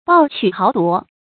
暴取豪夺 bào qǔ háo duó
暴取豪夺发音
成语注音 ㄅㄠˋ ㄑㄩˇ ㄏㄠˊ ㄉㄨㄛˊ